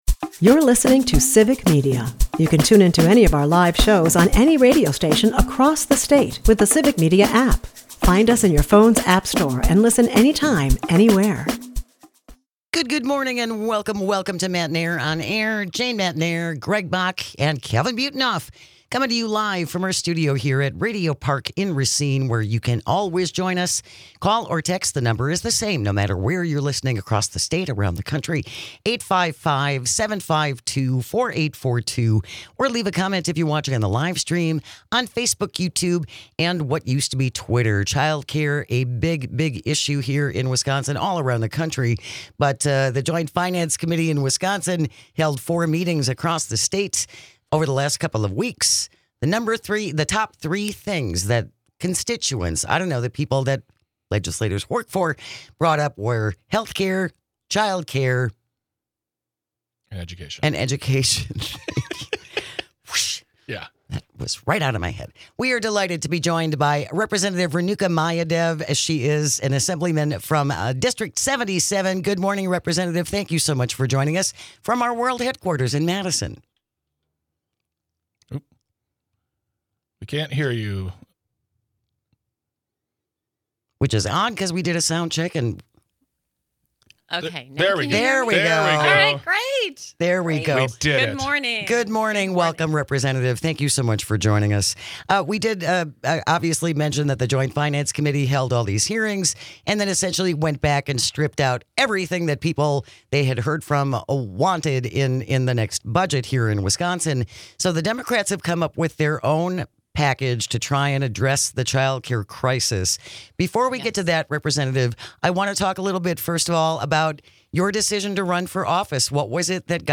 Newly elected Wisconsin State Assembly Member Renuka Mayadev (D-77) joins the show to discuss the bill she authored regarding the child care crisis here in Wisconsin. She speak what can and should be done to help businesses, parents, kids and yes, even politicians.